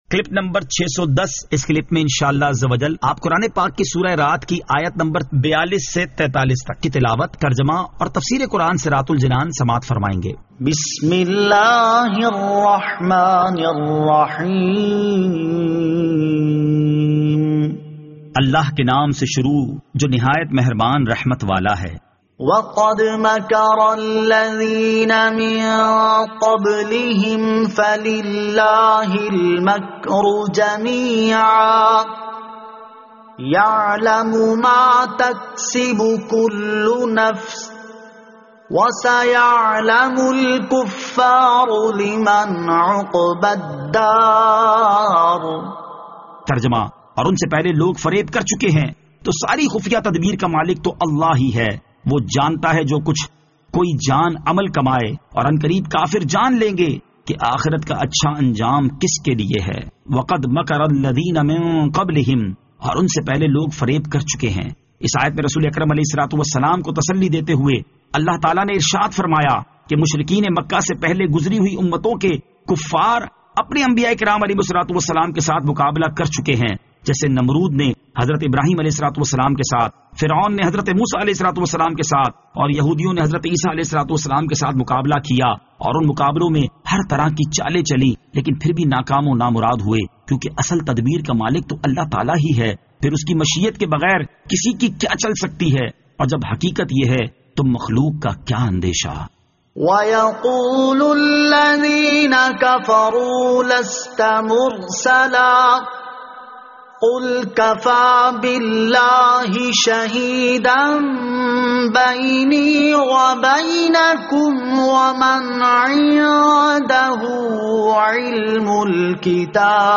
Surah Ar-Rad Ayat 42 To 43 Tilawat , Tarjama , Tafseer